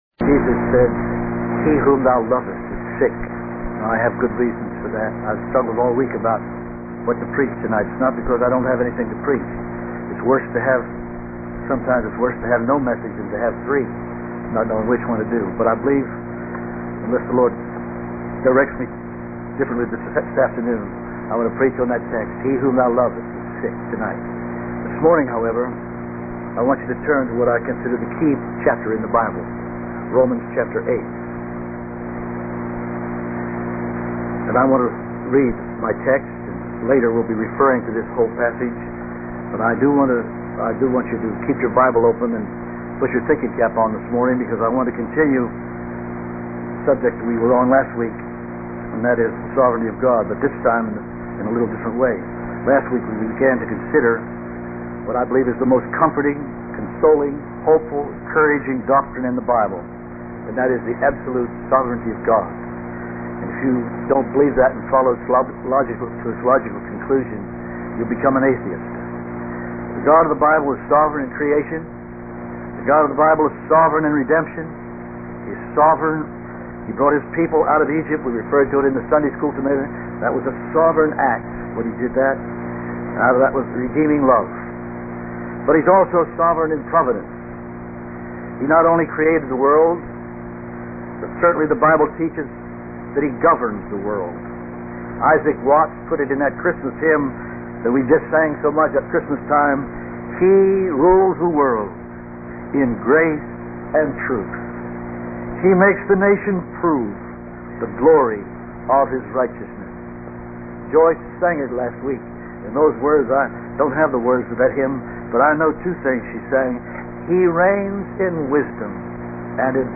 In this sermon, the speaker begins by using a hypothetical scenario to illustrate the importance of having complete control over all variables in order to achieve a goal.